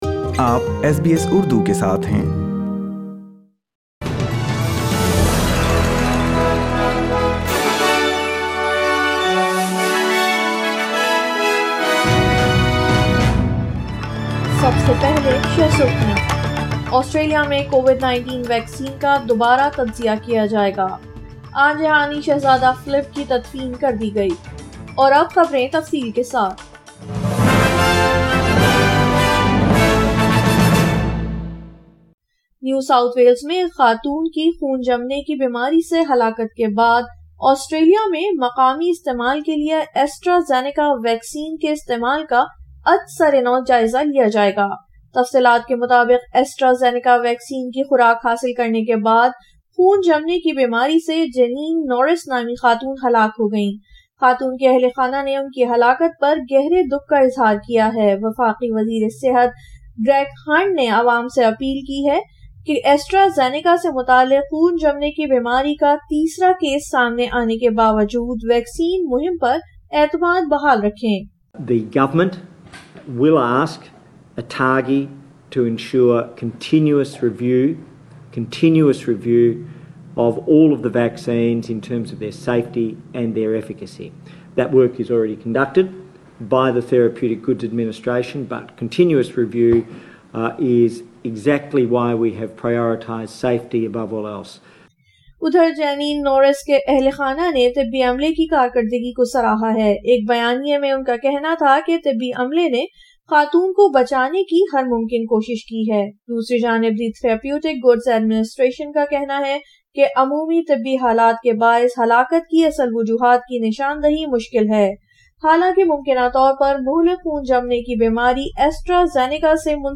Urdu News Sundya 18 April 2021